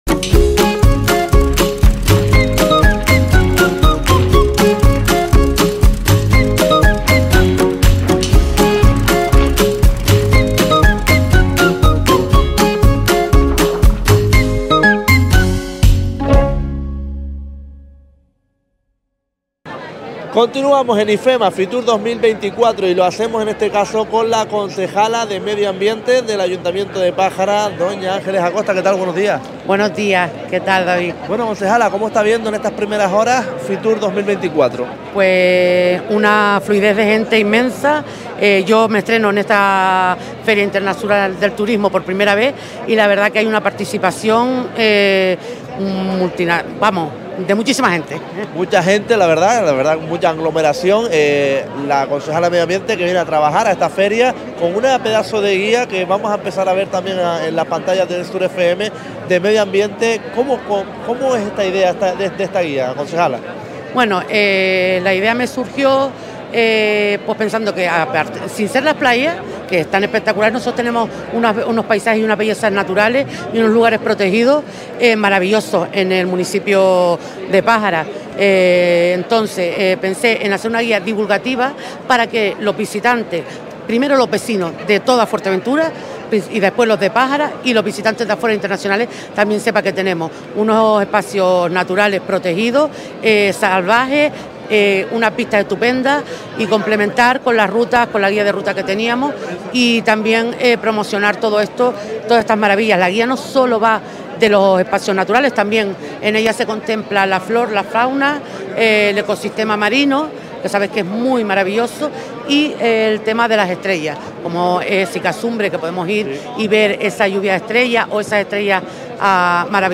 Fitur 2024: Entrevista a Ángeles Acosta 25/01/24
Entrevistamos a la concejala de medio ambiente del municipio de Pájara, Ángeles Acosta.